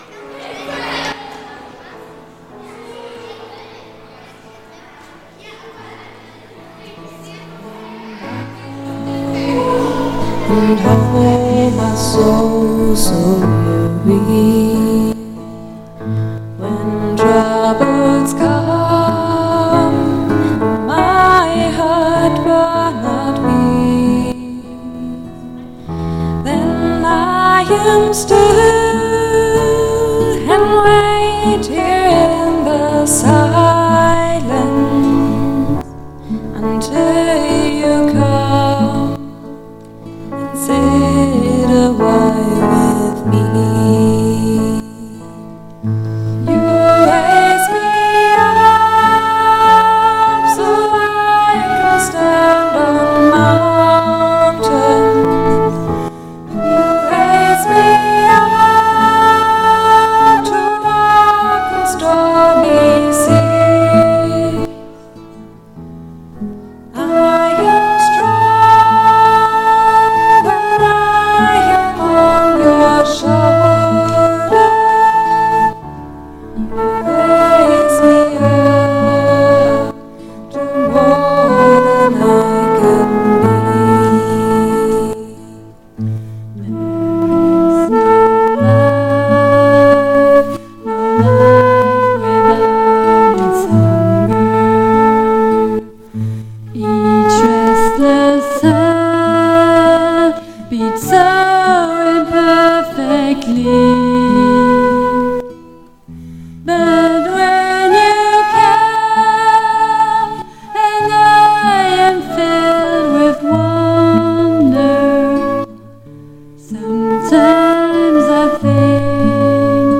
Aktuelle Predigt
Gottesdienst vom 25.01.2026 als Audio-Podcast Liebe Gemeinde, herzliche Einladung zum Gottesdienst vom 25. Januar 2026 in der Martinskirche Nierstein als Audio-Podcast.